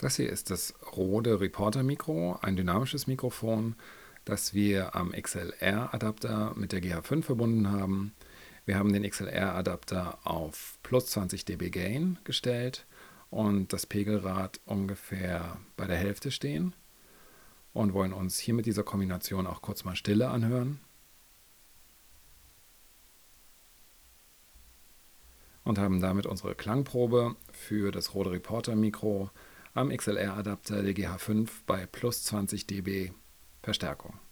Als nächstes folgt der Härtetest mit dynamischem Mikrofon.
Rode Reporter dynamisches Mikro bei 20 dB Boost am XLR-Adapter
Beim Test mit dem dynamischen Mikrofon gerät der XLR-Adapter - wie erwartet - an seine Grenzen.
RodeReporter_Dyna_XLR_20dbBoost_GH5_norm.wav